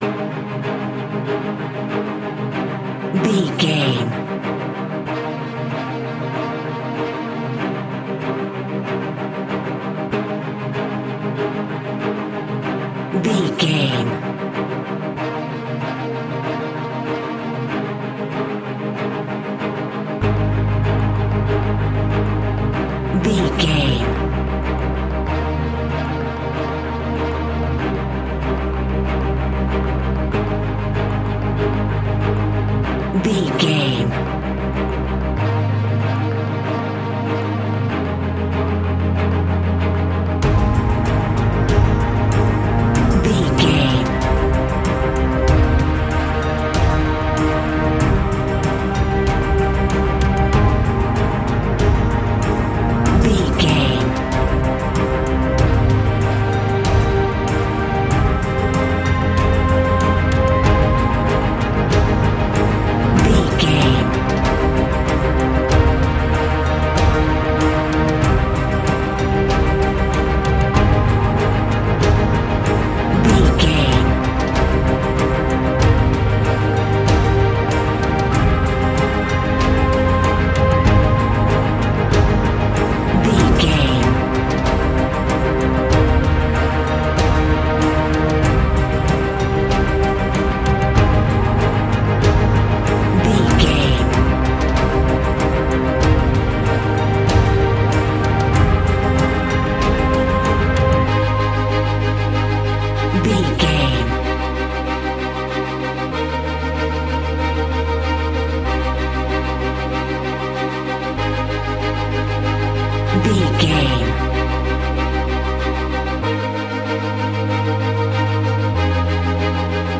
Epic / Action
Fast paced
In-crescendo
Uplifting
Aeolian/Minor
dramatic
powerful
strings
brass
percussion
synthesiser